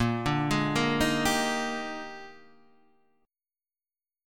Bb13 chord